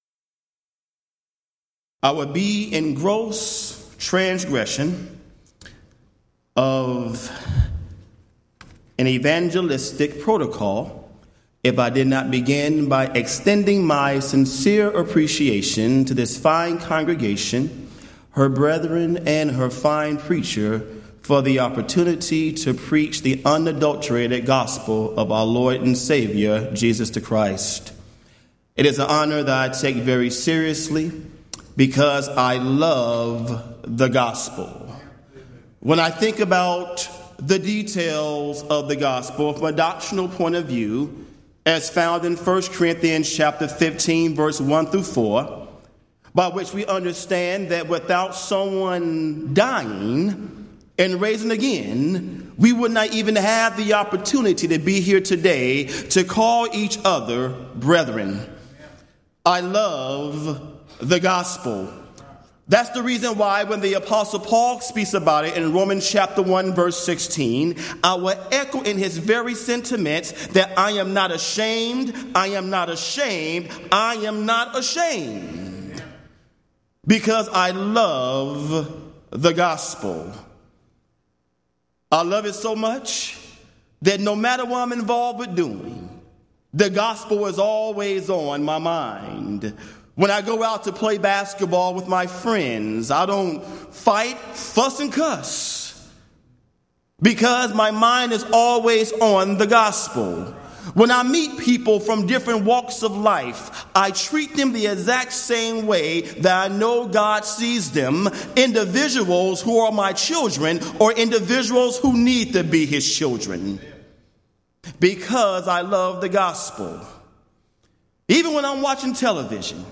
Event: 21st Annual Gulf Coast Lectures Theme/Title: Jesus The Christ
If you would like to order audio or video copies of this lecture, please contact our office and reference asset: 2014GulfCoast02